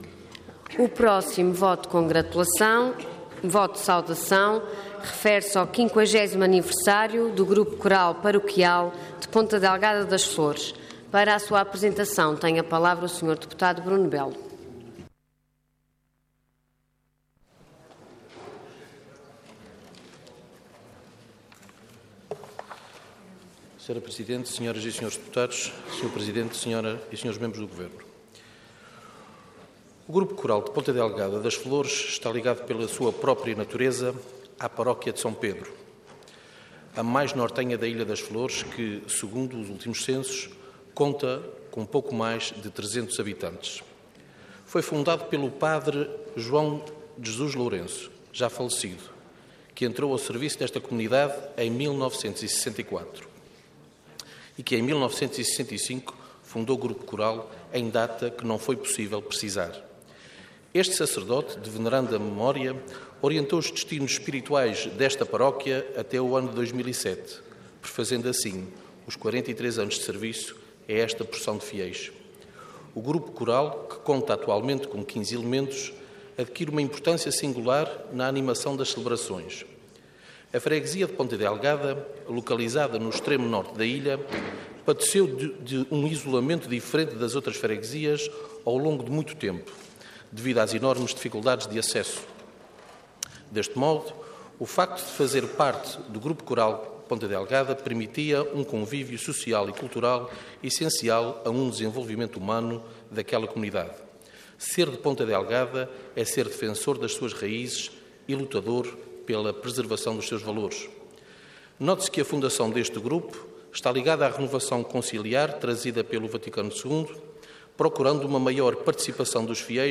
Detalhe de vídeo 13 de janeiro de 2016 Download áudio Download vídeo Processo X Legislatura 50º Aniversário do Grupo Coral Paroquial de Ponta Delgada das Flores Intervenção Voto de Saudação Orador Bruno Belo Cargo Deputado Entidade PSD